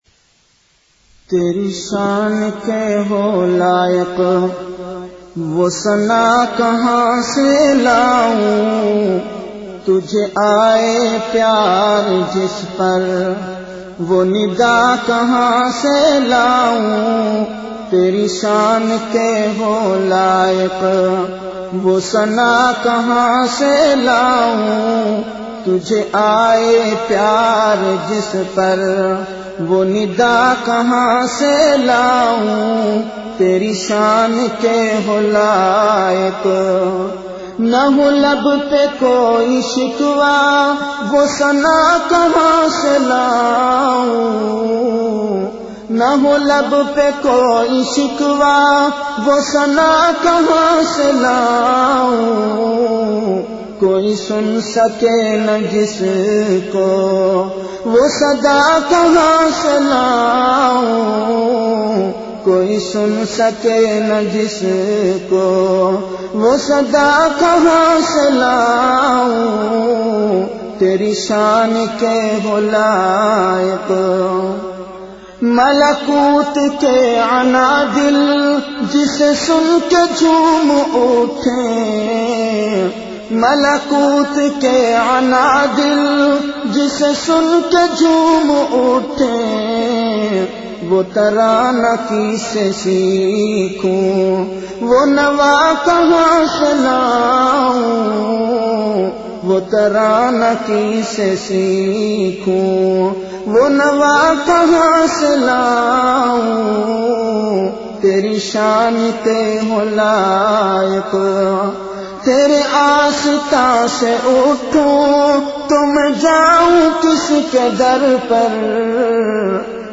CategoryAshaar
Event / TimeAfter Isha Prayer